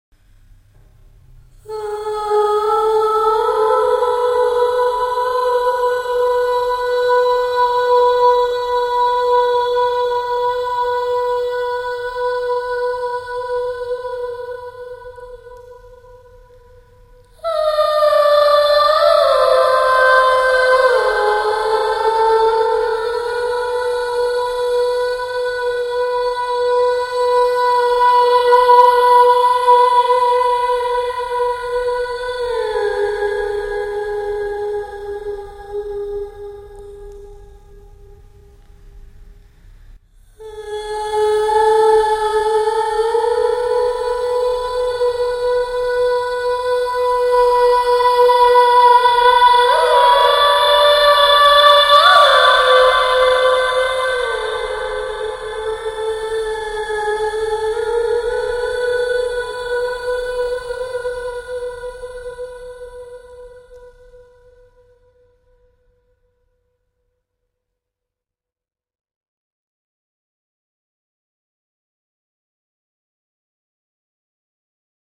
Tiếng Ma Kêu mp3